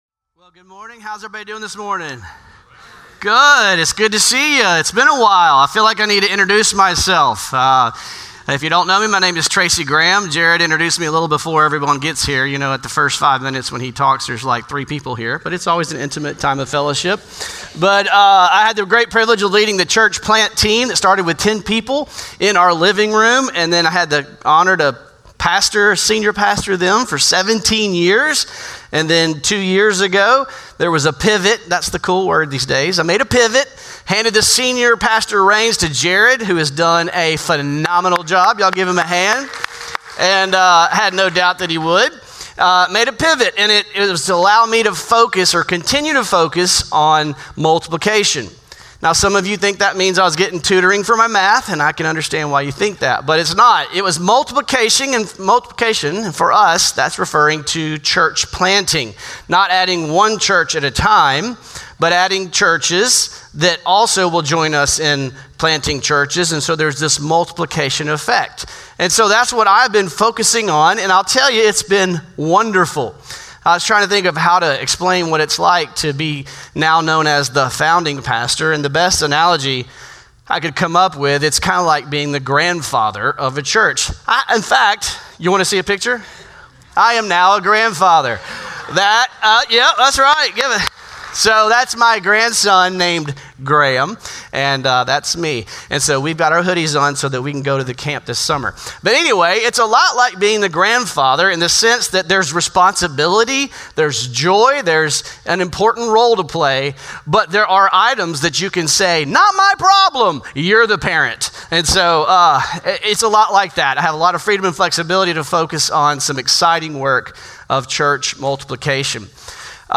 Norris Ferry Sermons June 1, 2025 -- Why We Multiply -- Vision 2025 Week 1 Jun 01 2025 | 00:35:34 1x 00:00 / 00:35:34 Subscribe Share Spotify RSS Feed Share Link Embed